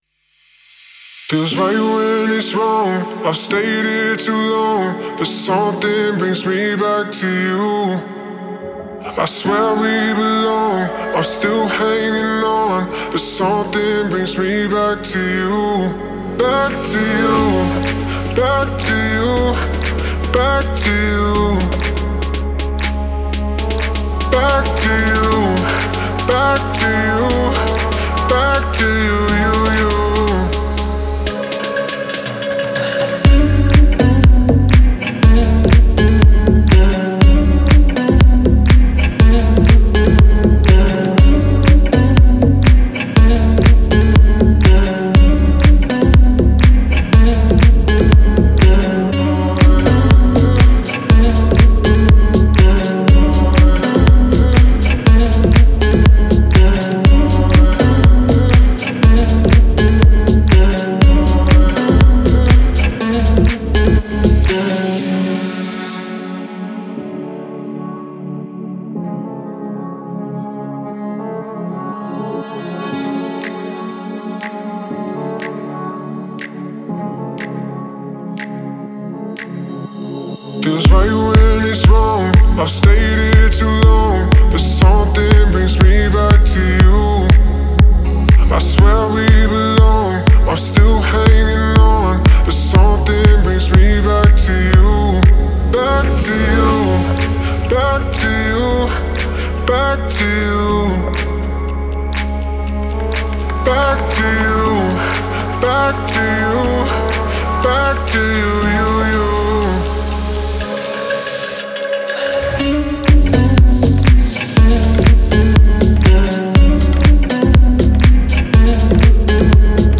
который сочетает в себе элементы поп и инди-рока.